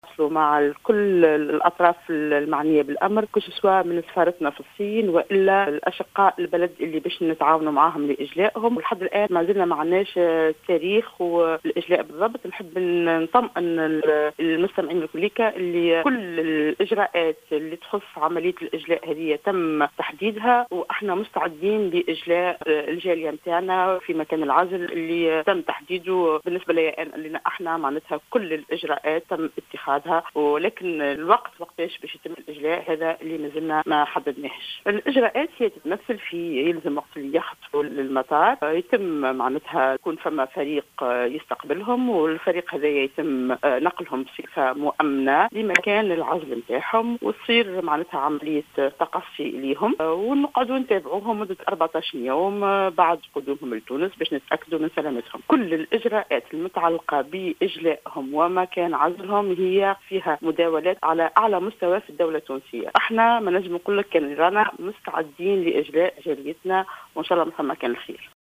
أكدت المديرة العامة لمرصد الأمراض الجديدة والمستجدة نصاف بن علية نصاف بن علية في تصريح لـ "الجوهرة أف أم"، أنه سيتمّ إحالة التونسيين الذين سيتم اجلاؤهم من ووهان، على الحجر الصحي، في تعليقها على خبر أوردته وكالة الأنباء الجزائرية اليوم بخصوص بدء عملية اجلاء تونسيين على متن طائرة جزائرية.